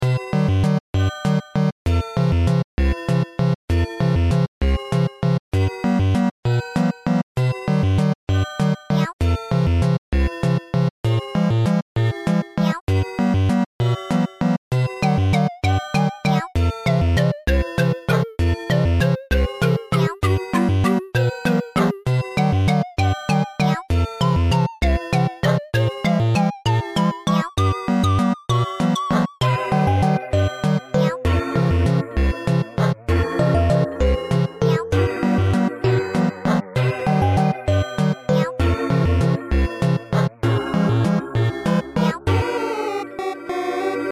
23.1.22 "pluto" -- this one was actually made with pandorasbox. it's a theme song for one of my old ocs :) he's a clown